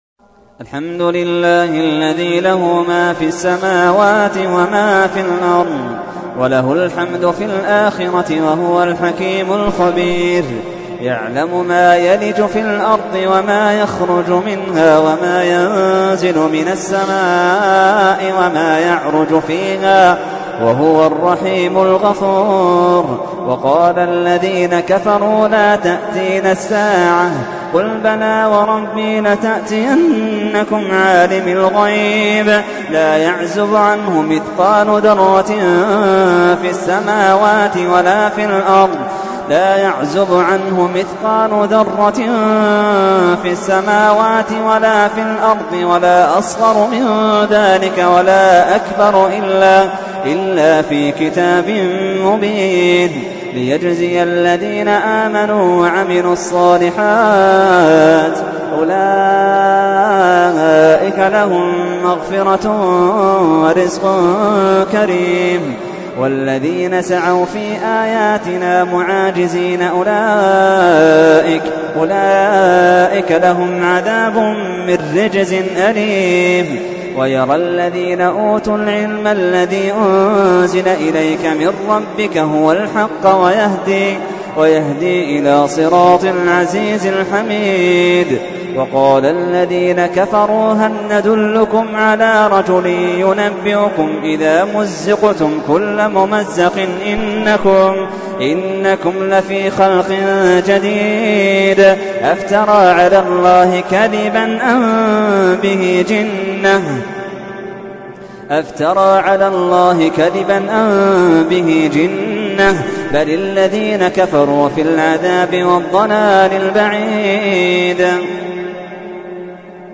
تحميل : 34. سورة سبأ / القارئ محمد اللحيدان / القرآن الكريم / موقع يا حسين